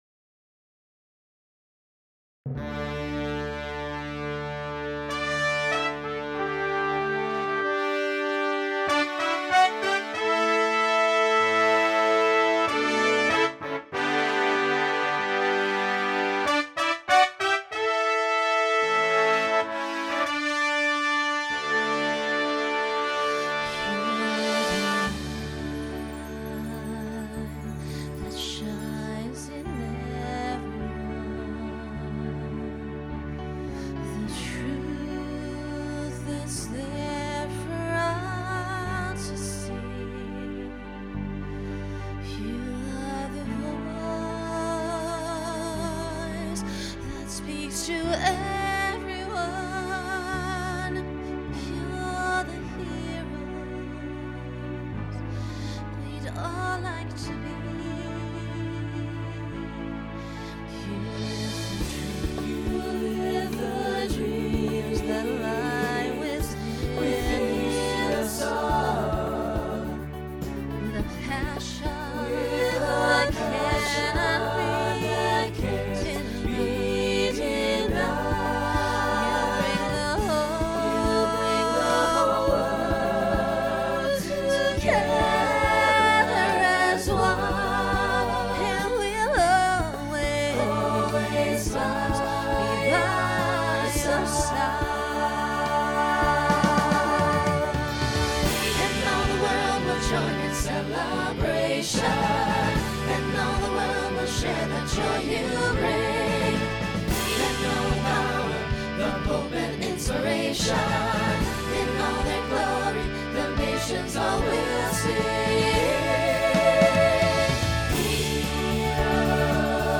Genre Pop/Dance
Ballad , Solo Feature Voicing SATB